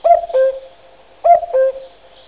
KUKACKA.mp3